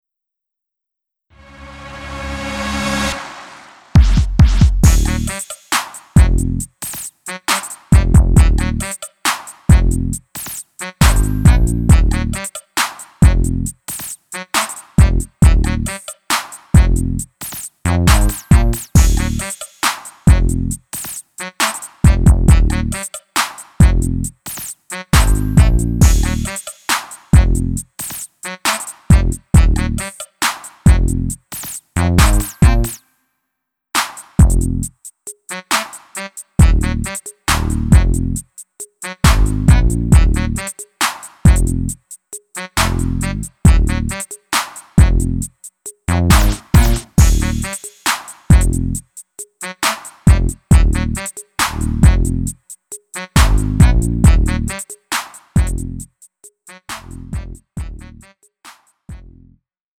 음정 -1키 3:12
장르 구분 Lite MR